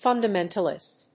/ˌfʌndʌˈmɛntʌlɪst(米国英語), ˌfʌndʌˈmentʌlɪst(英国英語)/
音節fun･da･men･tal･ist発音記号・読み方fʌ̀ndəméntəlɪst